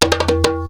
PERC 08.AI.wav